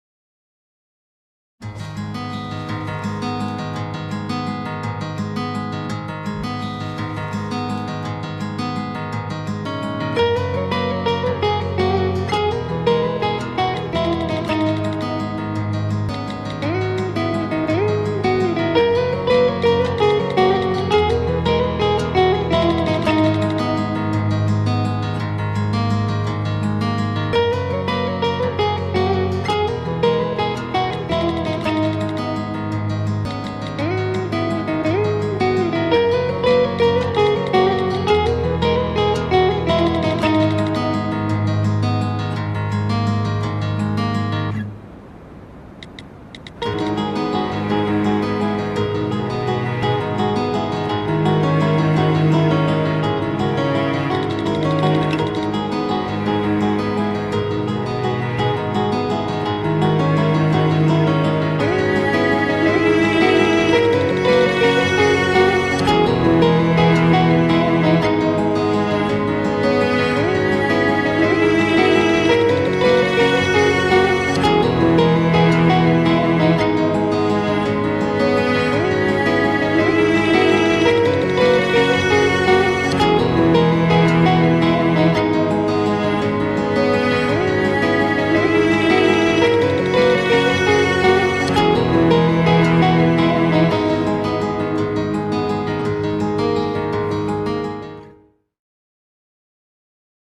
duygusal hüzünlü üzgün fon müziği.